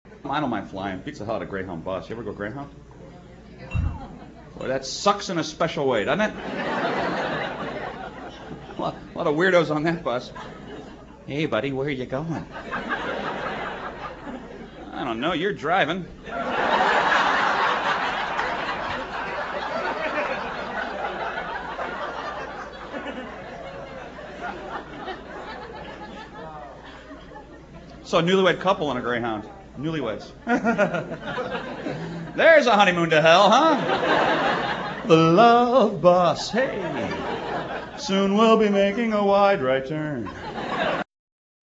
A Real Stand-Up Guy  (CD - $12.95)--Comedy Songs and Live Stand-Up